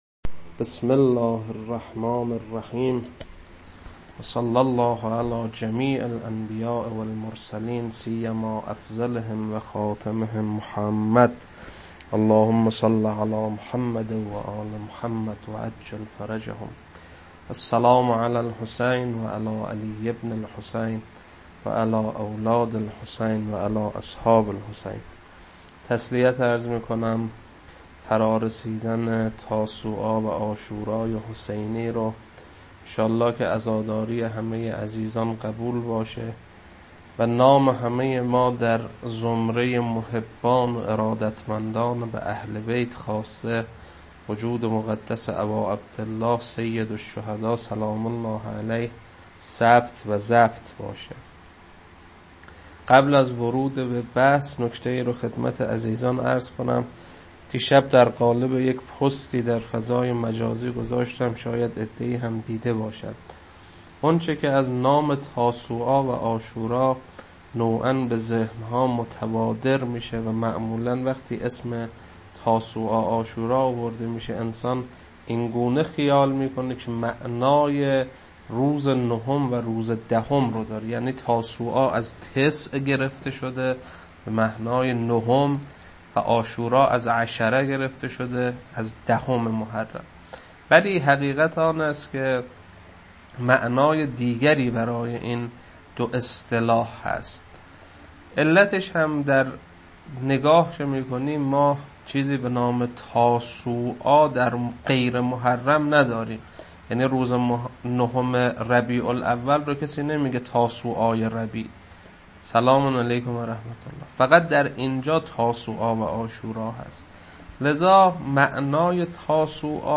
روضه شب عاشورا محرم 1400.mp3
روضه-شب-عاشورا-محرم-1400.mp3